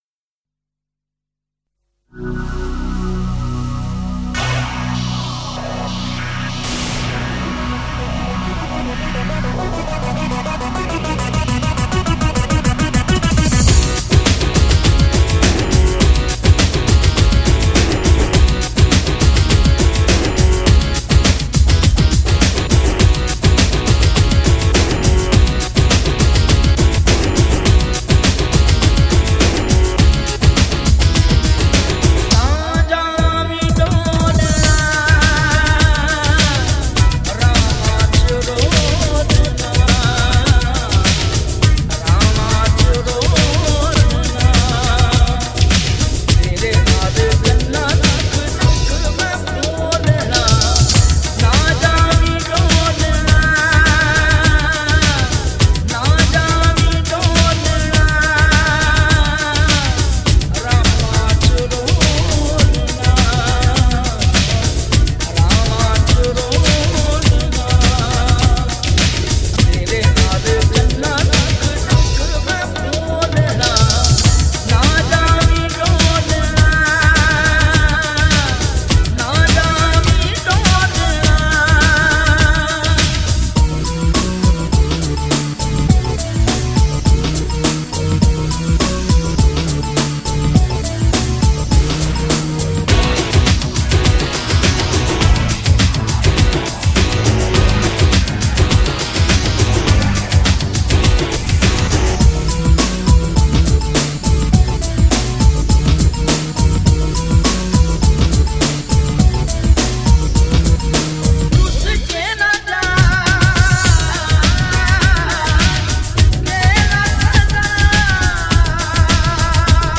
Qawwalis